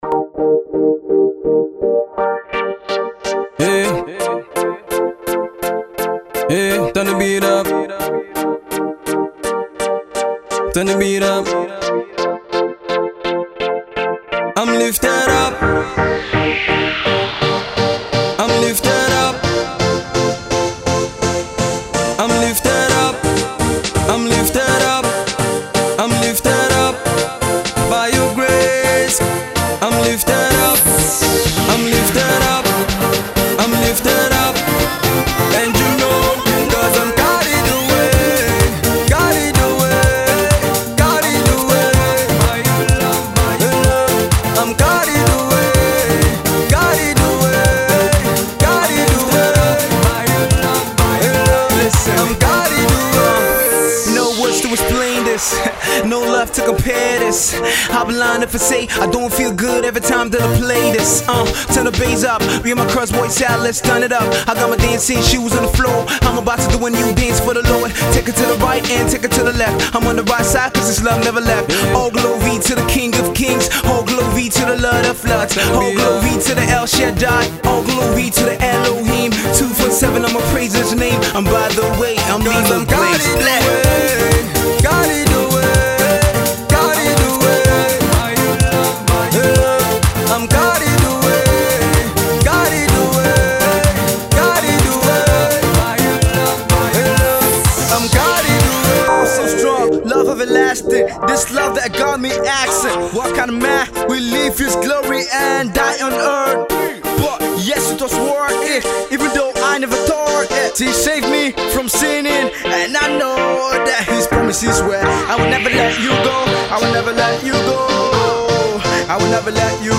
rappers
with an infectious hook